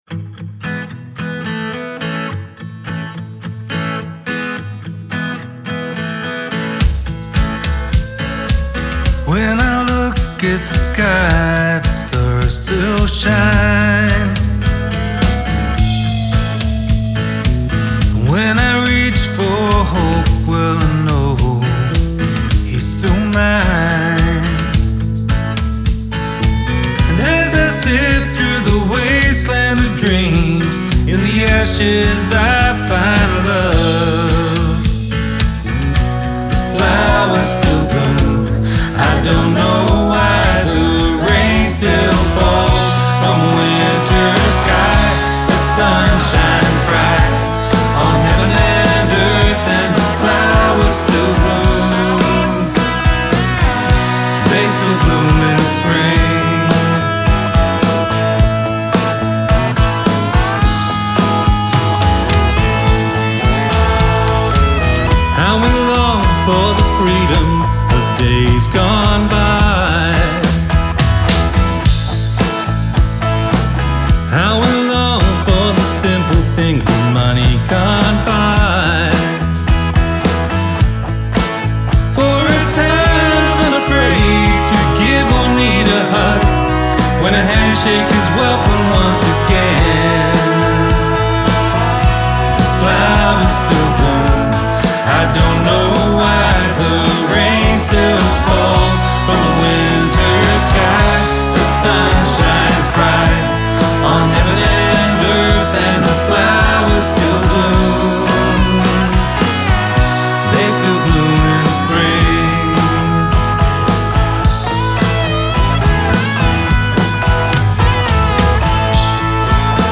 edgy Gospel tune